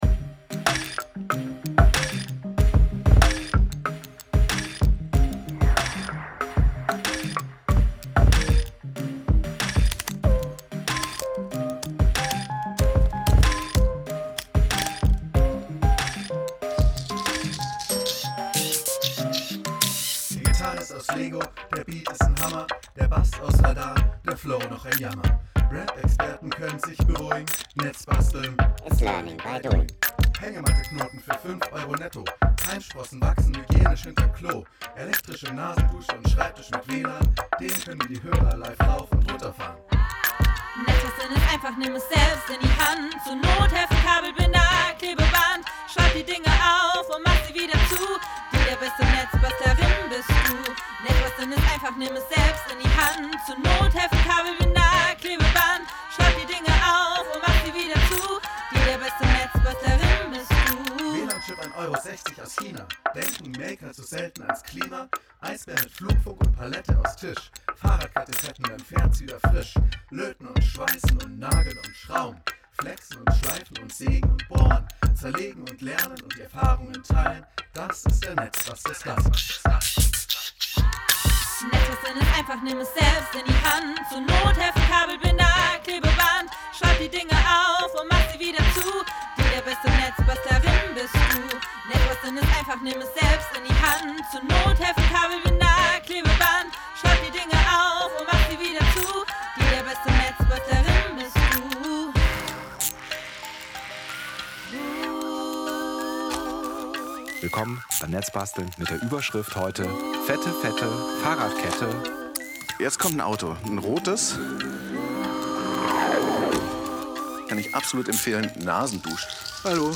Zur Feier der 150igsten Jubiläumsausgabe von Netzbasteln bauen wir einen Hit – aus 100 Prozent Werkstattgeräuschen!